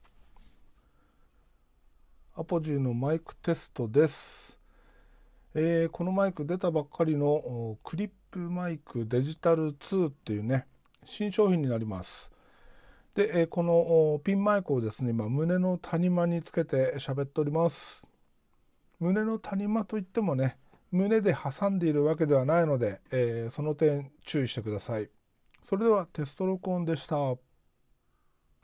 さっき届いたApogeeのピンマイクでテスト録音してみた
ちょっと抜けが良くないな…
実際使うには編集時に手を加えないとダメっぽいけど、ピンマイクならこんなもん？